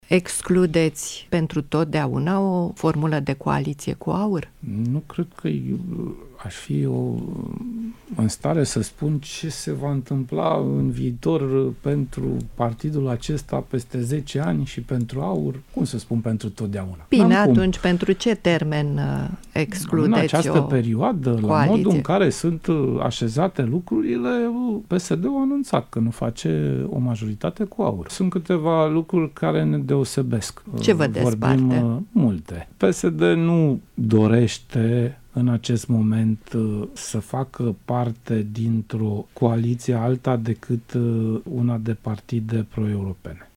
După ce, în ultima vreme, Partidul Social Democrat a negat o eventuală alianță cu AUR, acum liderul PSD și-a mai „nuanțat” părerea. Sorin Grindeanu a spus, într-un interviu acordat Radio România Actualități, că, deși acum o astfel de variantă nu este posibilă, pe termen lung nu exclude acest scenariu.